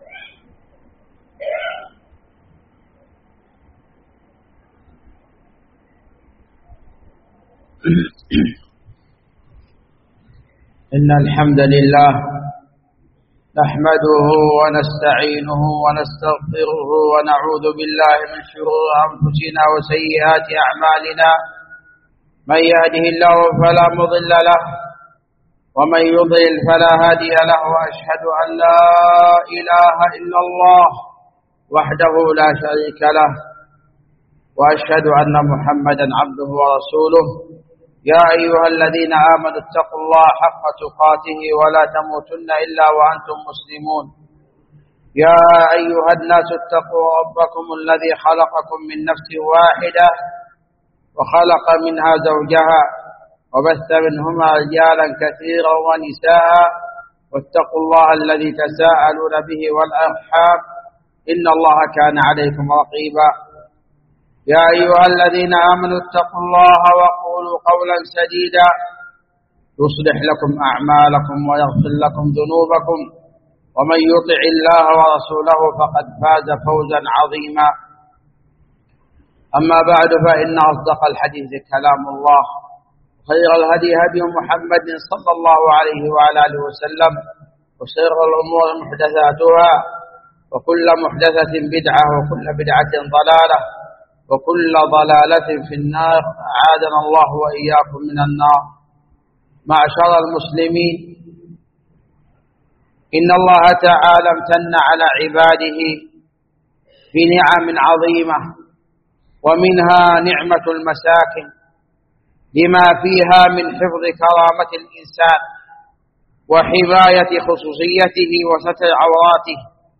خطبة التحذير من الجشع والطمع في رفع أسعار العقار
جامع الملك عبدالعزيز باسكان الخارش بصامطة